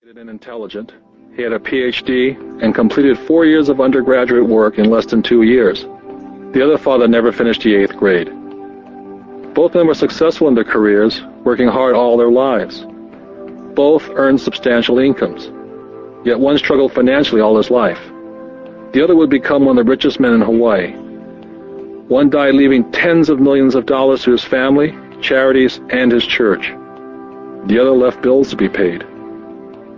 Rich Dad Poor Dad Audio Book